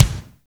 Index of /90_sSampleCDs/Roland L-CD701/KIT_Drum Kits 4/KIT_Attack Kit
KIK ATTAC01R.wav